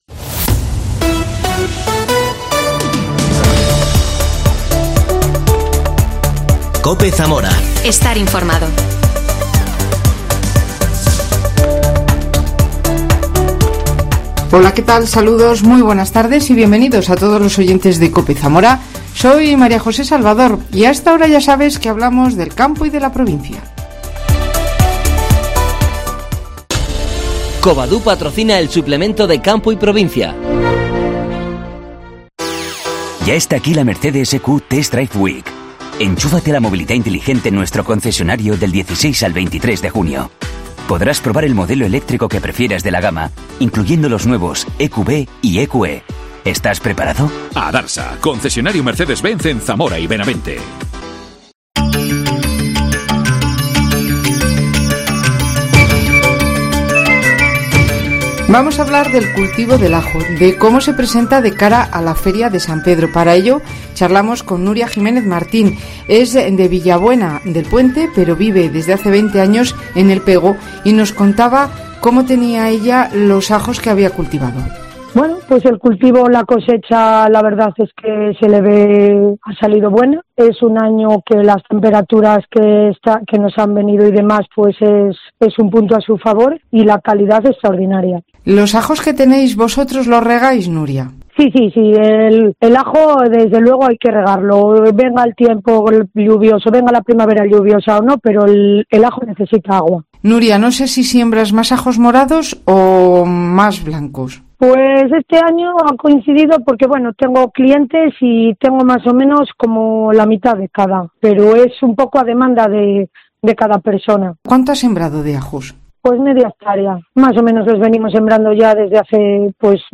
AUDIO: Entrevista a una ajera de El PegoCobadu